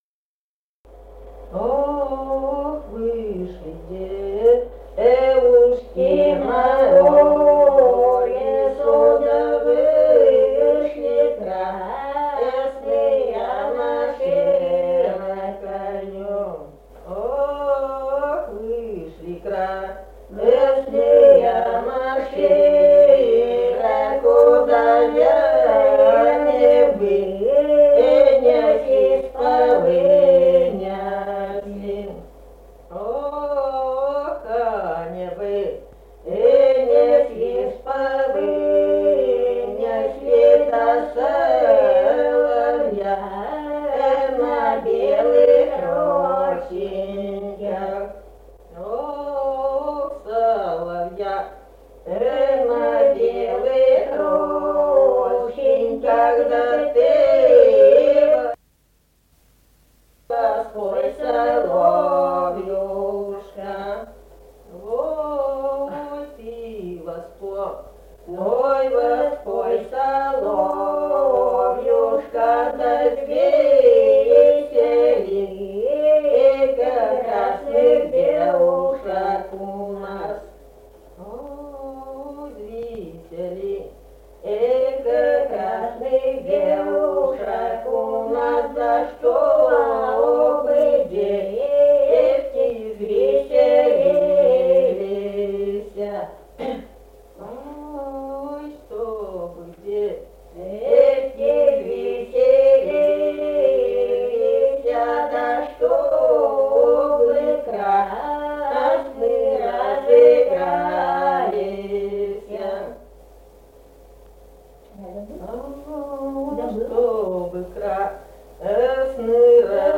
Сидит Дрема Ох, вышли девушки на улицу (хороводная «лужошная») Республика Казахстан, Восточно-Казахстанская обл., Катон-Карагайский р-н, с. Белое (с 2009 г. каз.